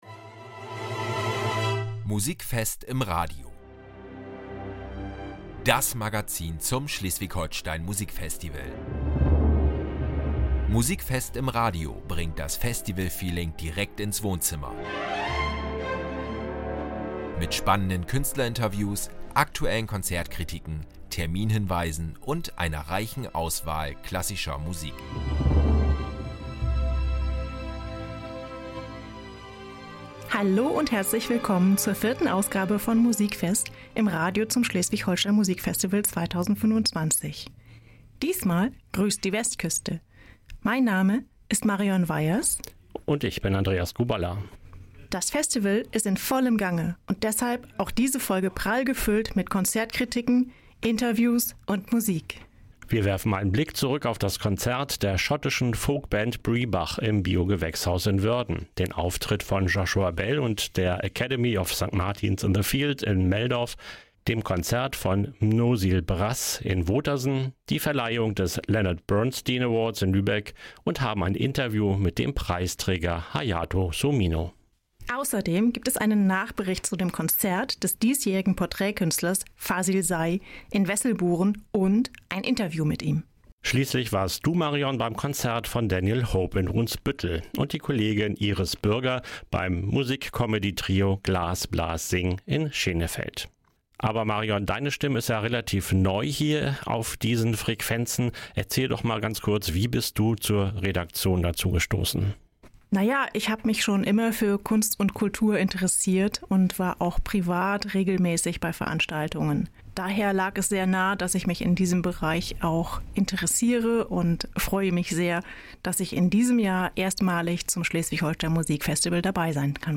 Beschreibung vor 8 Monaten In der vierten Folge von “Musikfest im Radio” geht es um musikalische Höhepunkte und starke Persönlichkeiten: Die Redaktion berichtet in mehreren Konzertkritiken von ihren Festivalerlebnissen. Wir stellen Bernstein Award-Preisträger Hayato Sumino vor – inklusive Interview mit dem jungen Klaviervirtuosen – und sprechen mit dem Porträtkünstler 2025, Fazıl Say, über seine Musik und seine Verbindung zum SHMF. Außerdem: weitere Festivalmomente zum Hören und Entdecken.